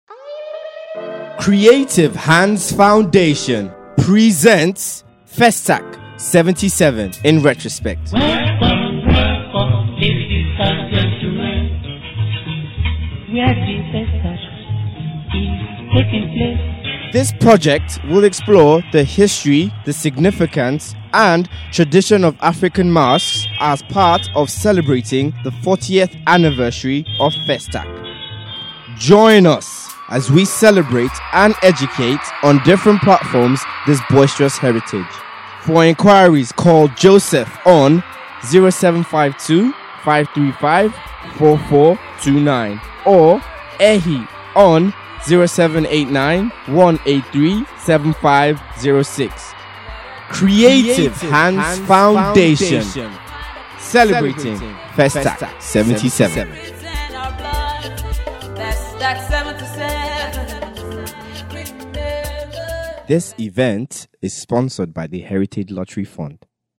On the 7th of October, the first episode tagged “FESTAC ’77 In Retrospect Talk show” aired.
The interactive environment between the CHF volunteers, CHF radio hosts, callers, and people sending text messages helped to achieve a creative dynamics in the shows. The live broadcast provided and opened evidence to public scrutiny in real-time, unlike a private interview.